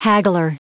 Prononciation du mot : haggler
haggler.wav